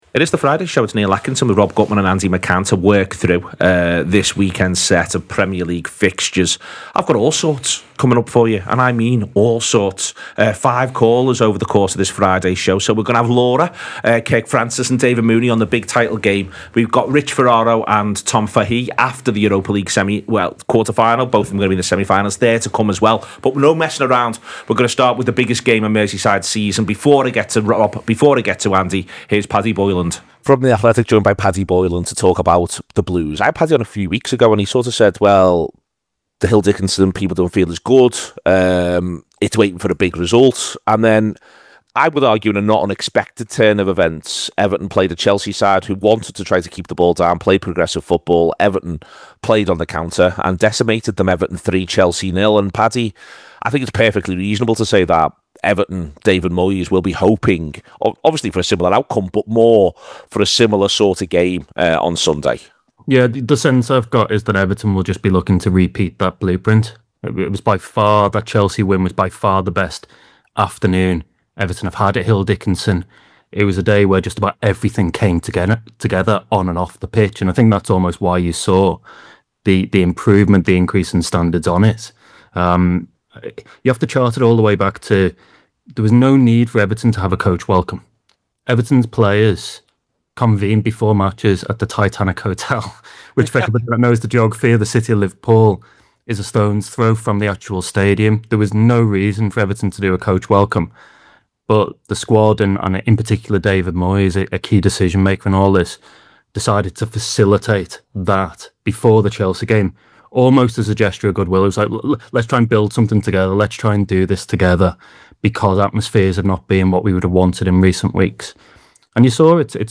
Below is a clip from the show – subscribe for more on the weekends Premier League fixtures